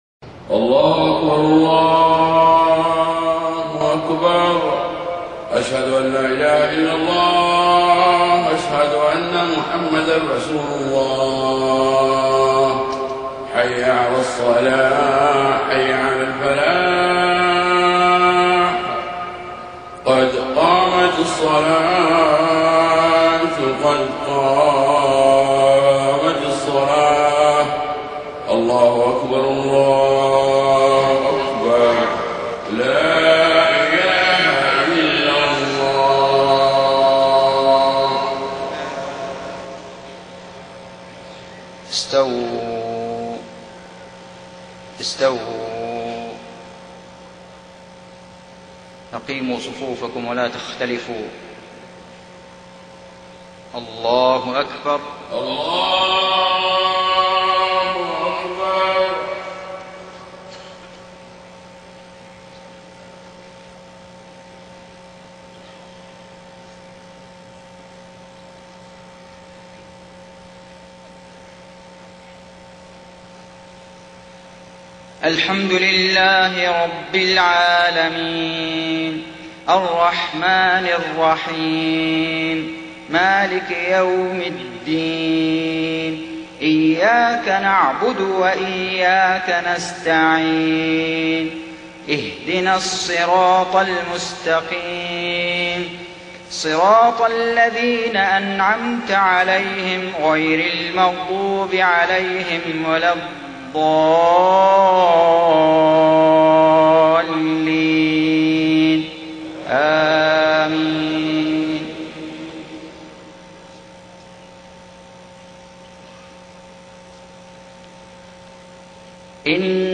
صلاة العشاء 1 صفر 1430هـ من سورة غافر 51-66 > 1430 🕋 > الفروض - تلاوات الحرمين